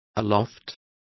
Complete with pronunciation of the translation of aloft.